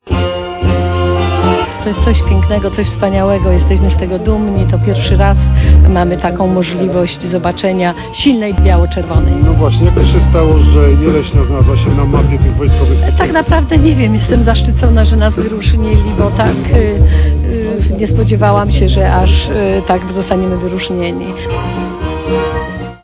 To, że jesteśmy w gronie kilkunastu miejscowości wytypowanych do organizacji pikniku jest dla nas i zaskoczeniem i wyróżnieniem, mówiła w czasie imprezy wójt Jeleśni Anna Wasilewska.